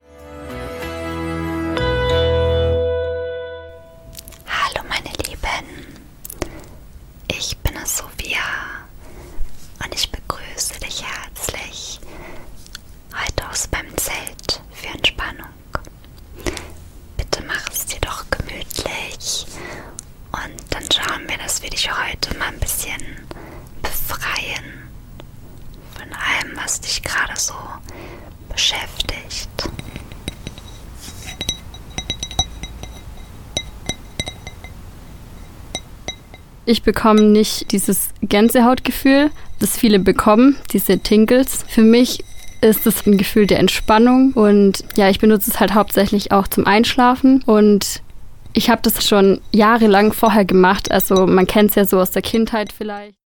251_Teaser_ASMR_Tonspur.mp3